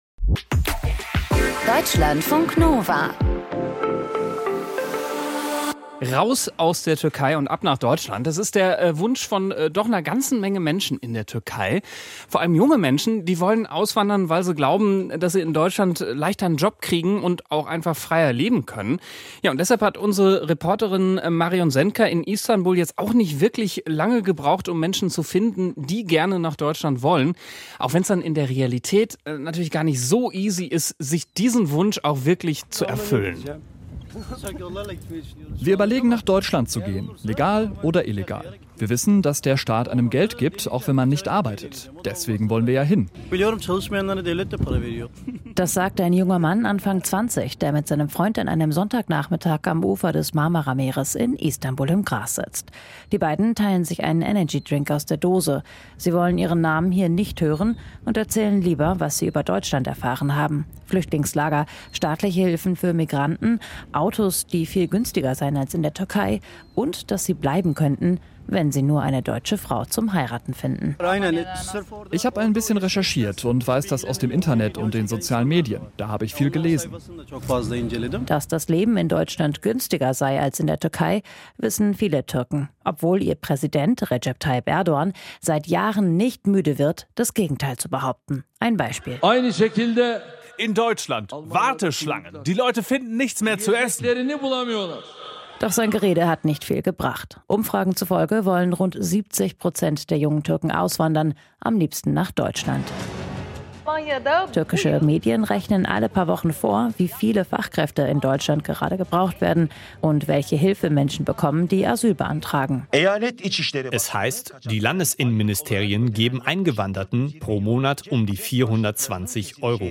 Das Interview im Deutschlandfunk Kultur greift kulturelle und politische Trends ebenso auf wie...